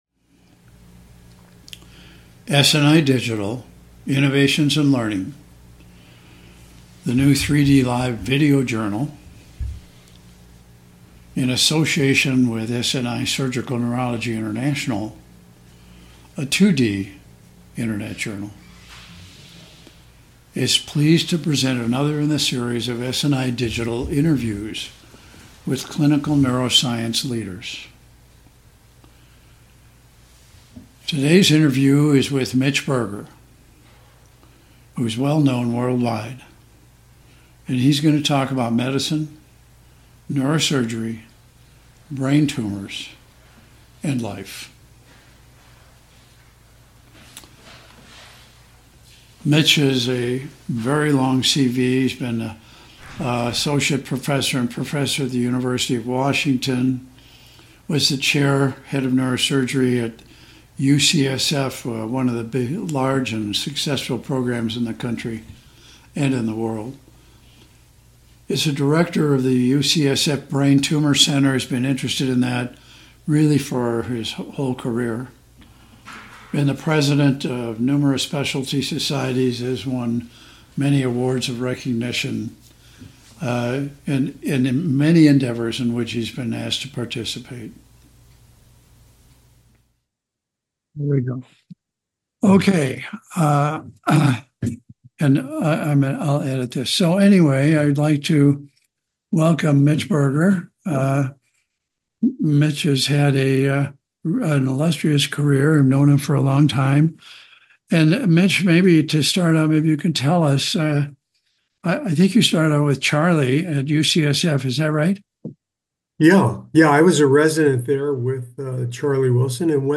Interview Neuroscience Expert; Neuro-oncology, Brain Mapping; Gross total surgical resection; New Advances in resection; Career and Life advice